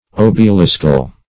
Obeliscal \Ob`e*lis"cal\, a. Formed like an obelisk.